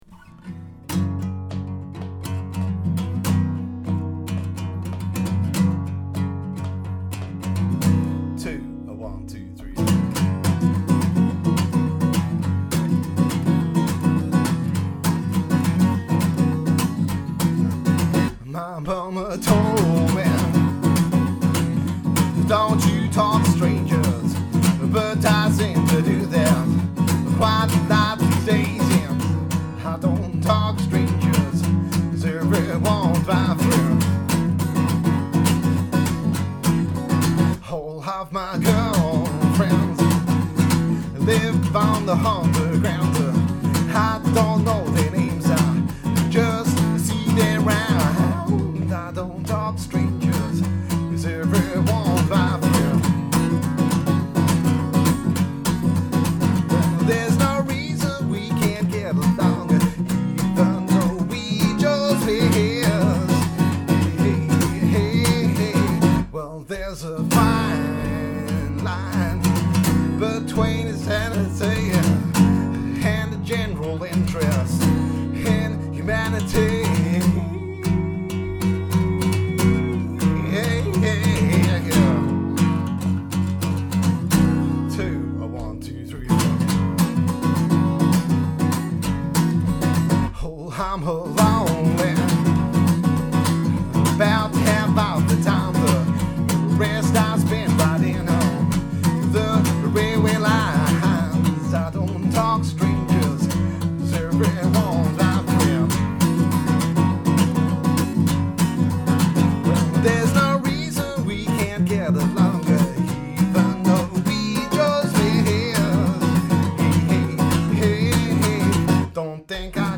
modern acoustic pop